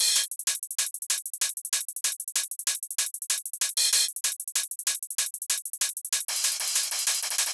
• 12 HH Loops: Enhance your beats with high-quality hi-hat loops that bring crispness and precision to your drum patterns.